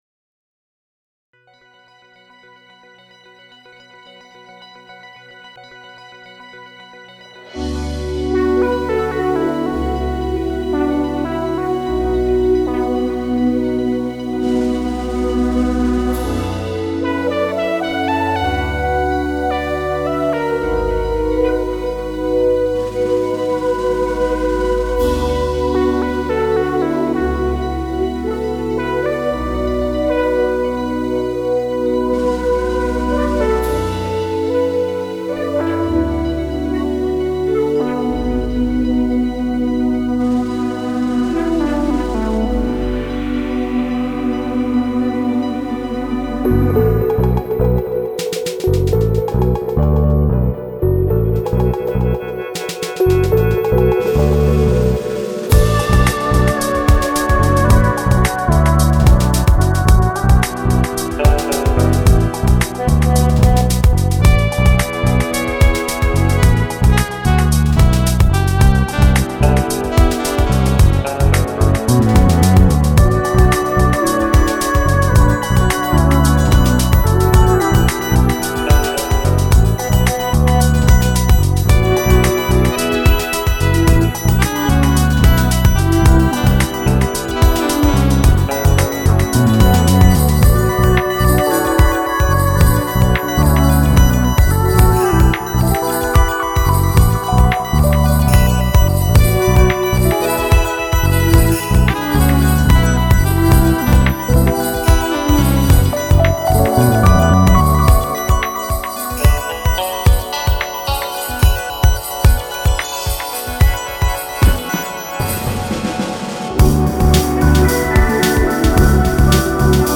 Genre: DowntempoTrip-Hop.